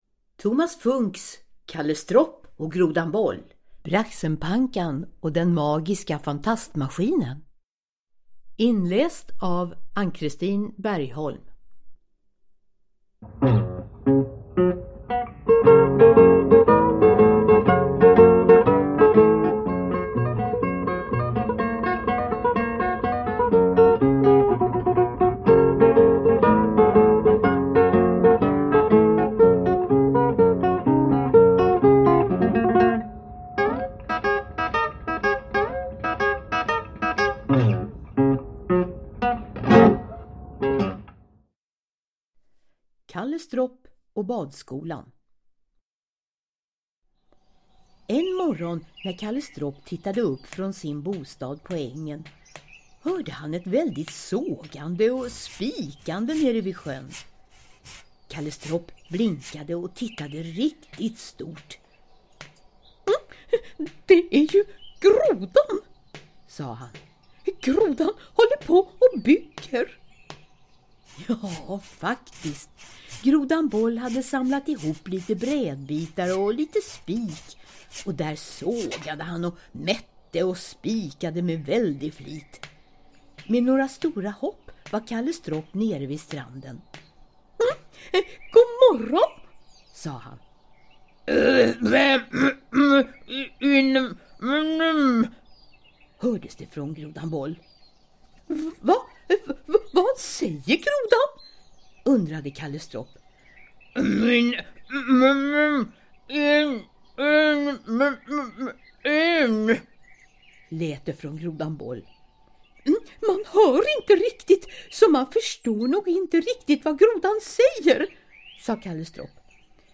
Thomas Funcks Kalle Stropp och Grodan Boll - Braxenpankan och den magiska fantastmaskinen / Ljudbok